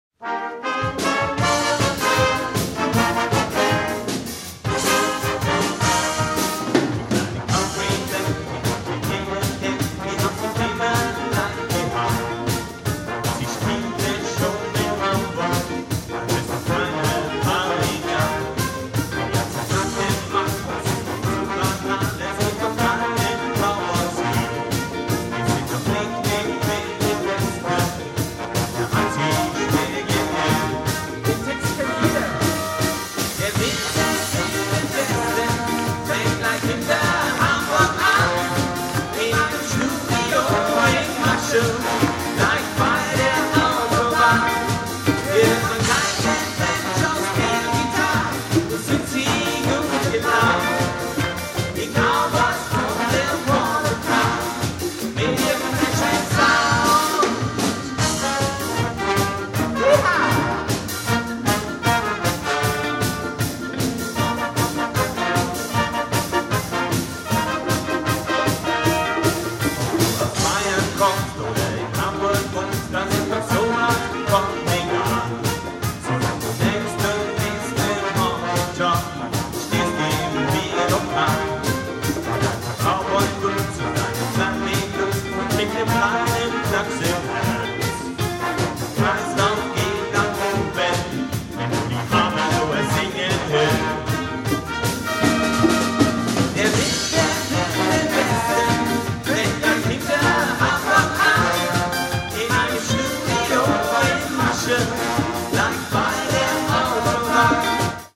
Konzert 2005 -Download-Bereich
-------Die Big Band-------